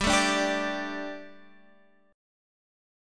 Gsus2 chord